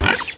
lechonk_ambient.ogg